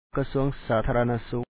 Pronunciation Notes 25
kasúaŋ sǎatháalana-súk Ministry of Public Health